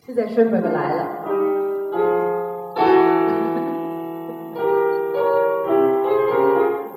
[32]这是整个奏鸣曲里最大的惊奇。